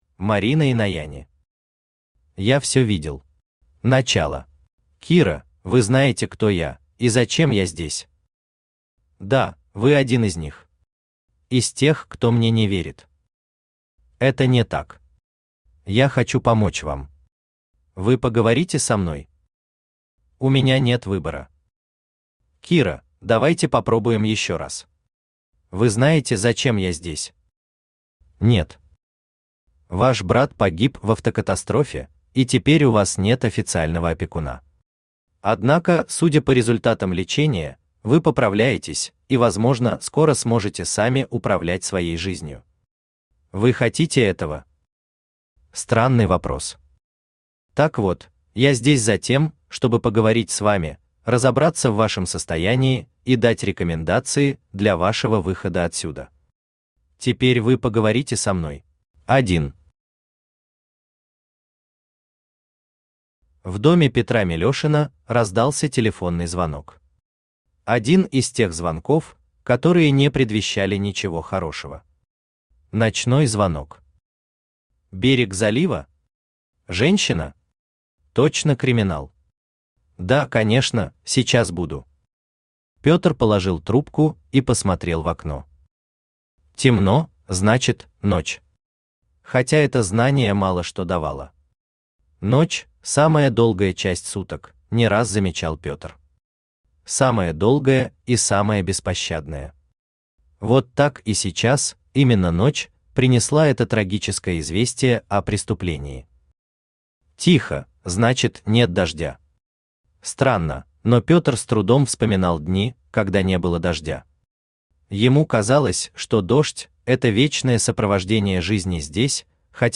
Аудиокнига Я всё видел… Начало | Библиотека аудиокниг
Начало Автор Марина Инаяни Читает аудиокнигу Авточтец ЛитРес.